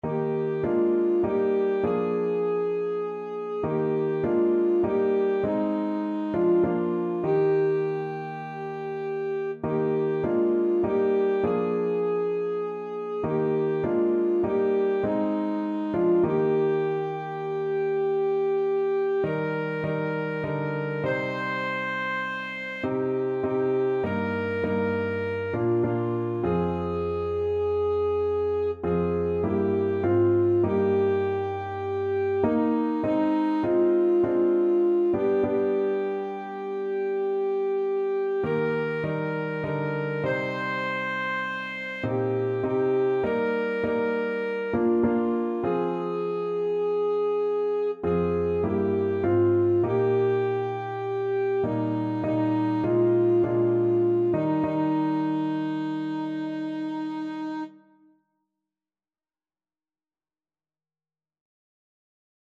Alto Saxophone version
4/4 (View more 4/4 Music)
Traditional (View more Traditional Saxophone Music)